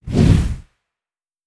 flare.wav